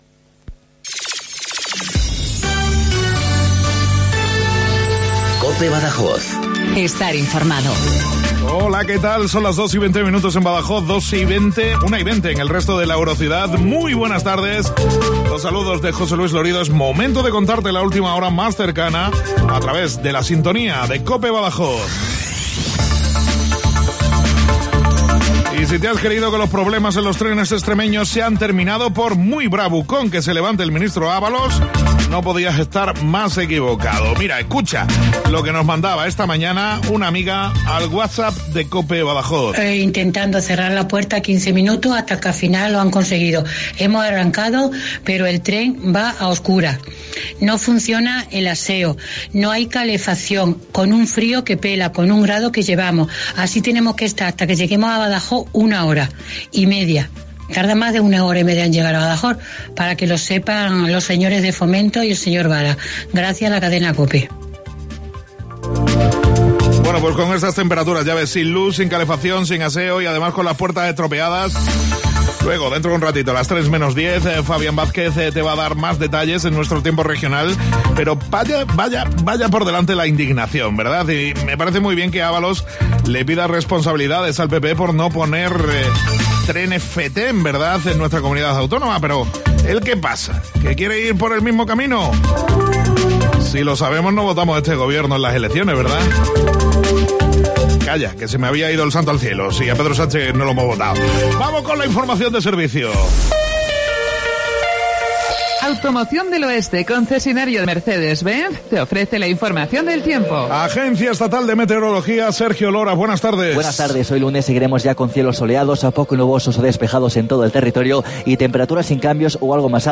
INFORMATIVO LOCAL BADAJOZ 14:20